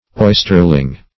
oysterling - definition of oysterling - synonyms, pronunciation, spelling from Free Dictionary
oysterling - definition of oysterling - synonyms, pronunciation, spelling from Free Dictionary Search Result for " oysterling" : The Collaborative International Dictionary of English v.0.48: Oysterling \Oys"ter*ling\, n. (Zool.) A young oyster.